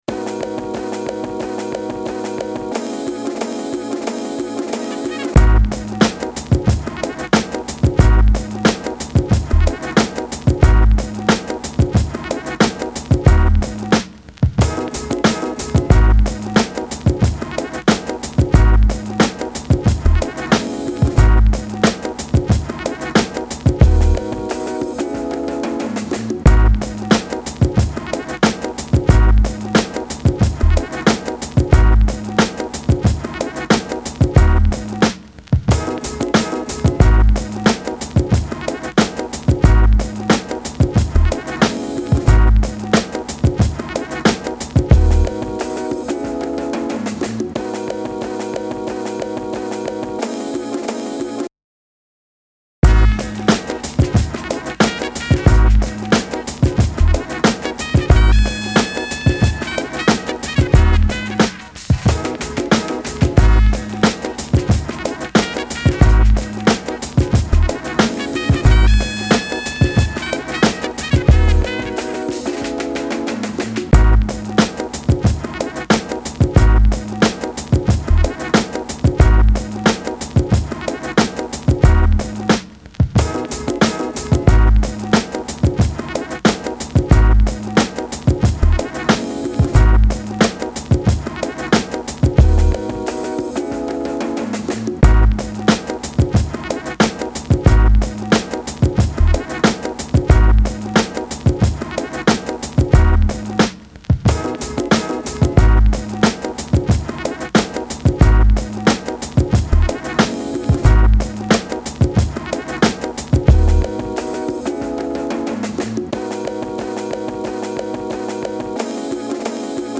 Instrumental | Acapella